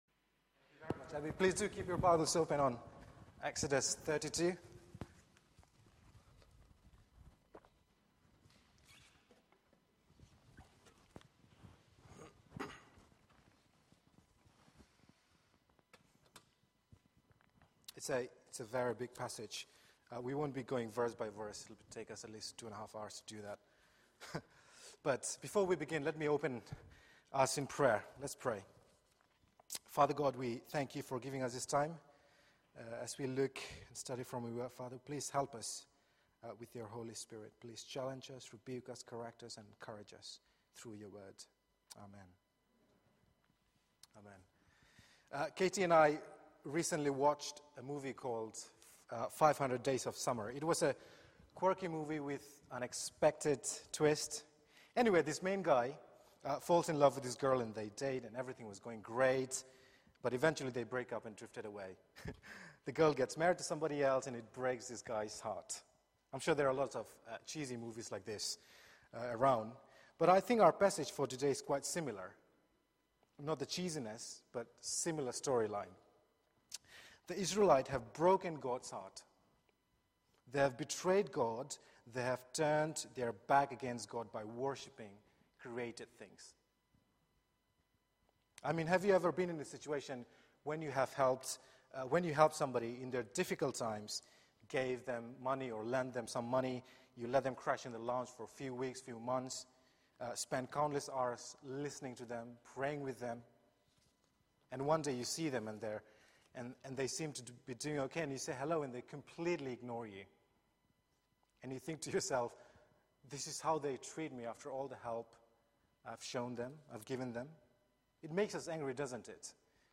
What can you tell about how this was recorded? Media for 4pm Service